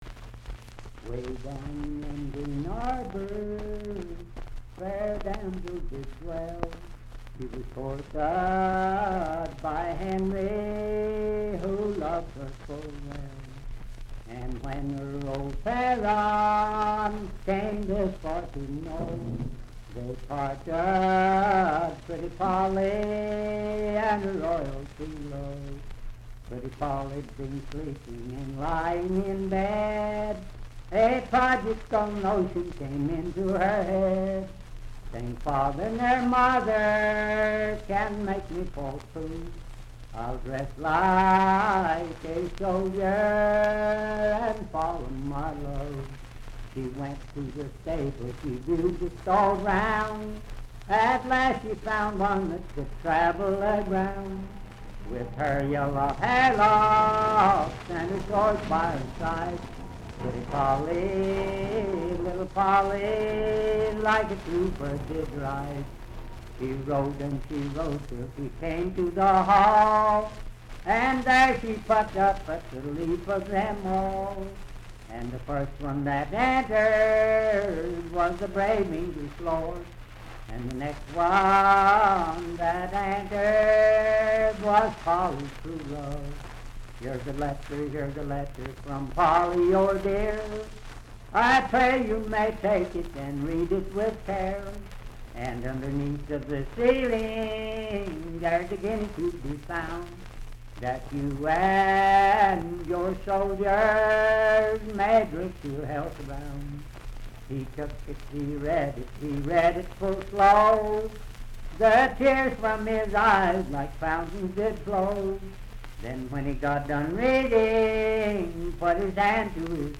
Unaccompanied vocal music
Voice (sung)
Wood County (W. Va.), Parkersburg (W. Va.)